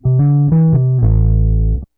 BASS 15.wav